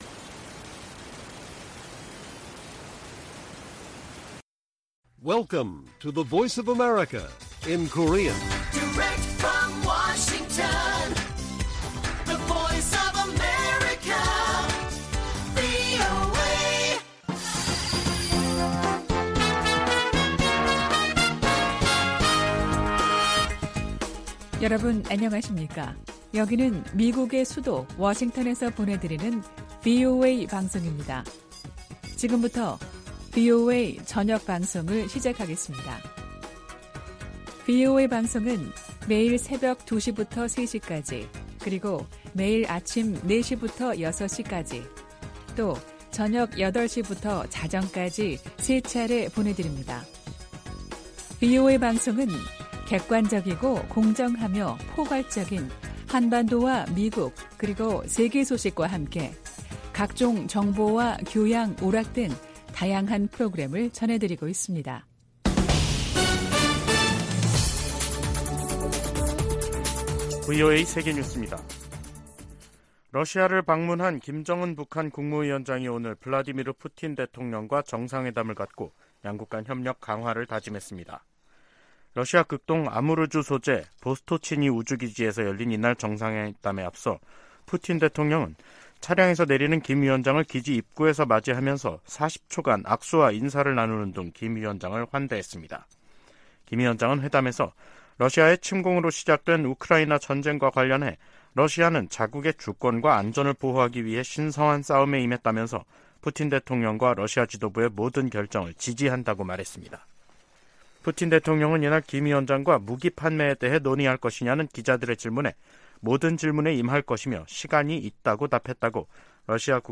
VOA 한국어 간판 뉴스 프로그램 '뉴스 투데이', 2023년 9월 13일 1부 방송입니다. 김정은 북한 국무위원장과 블라디미르 푸틴 러시아 대통령의 회담이 현지 시간 13일 오후 러시아 극동 우주기지에서 열렸습니다. 직전 북한은 단거리 탄도미사일 두 발을 동해상으로 발사했습니다. 미국 정부는 북한과 러시아의 무기거래에 거듭 우려를 나타내며 실제 거래가 이뤄지면 추가 행동에 나서겠다고 강조했습니다.